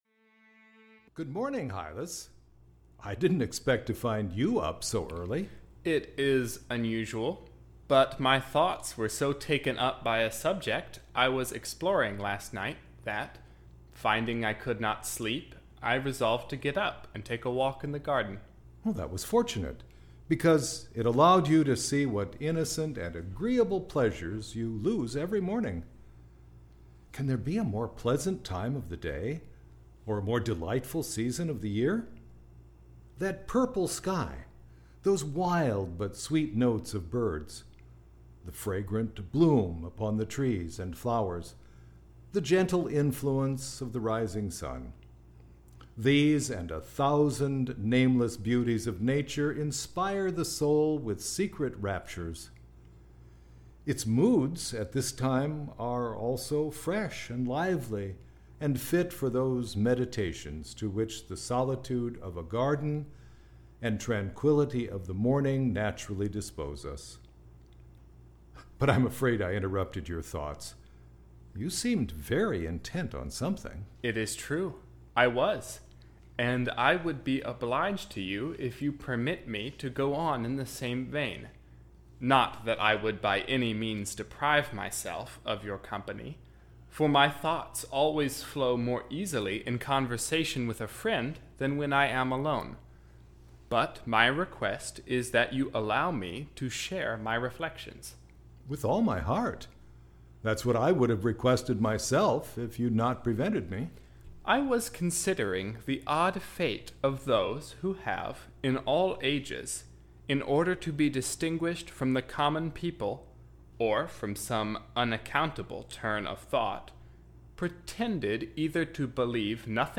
Audio knihaBerkeley’s Three Dialogues between Hylas and Philonous (EN)
Ukázka z knihy